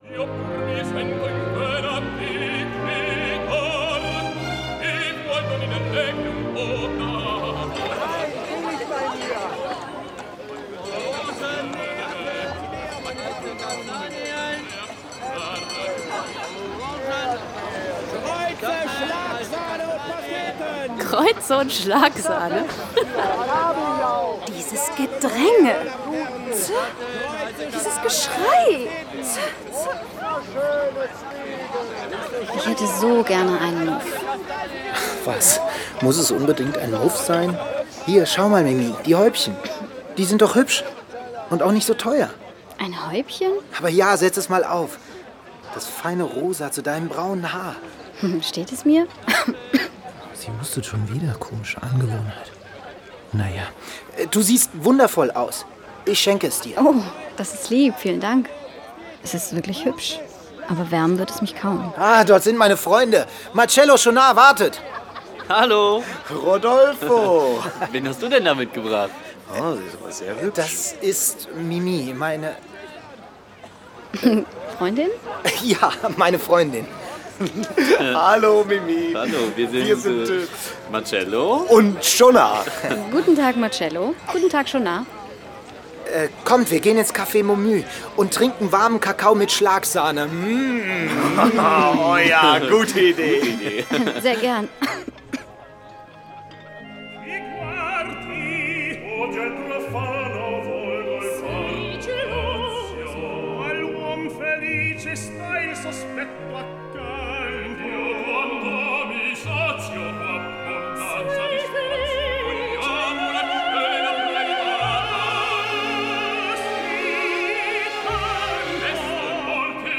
Hörspiele mit Musik